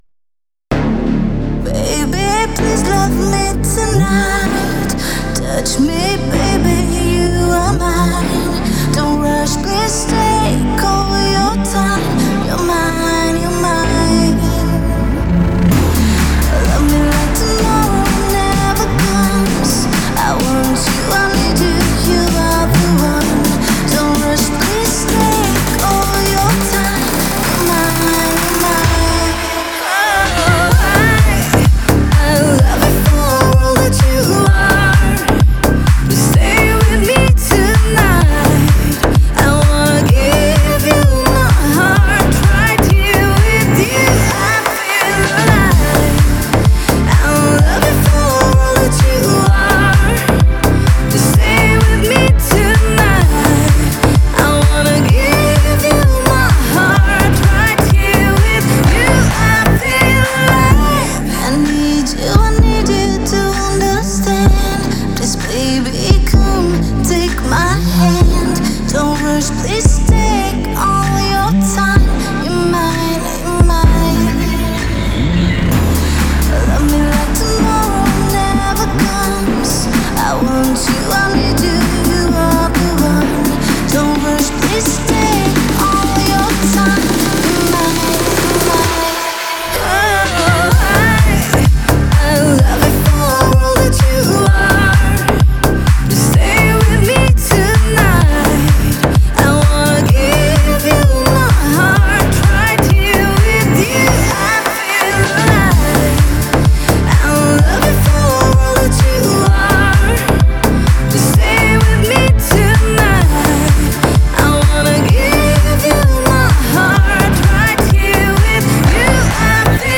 это энергичная танцевальная композиция в жанре EDM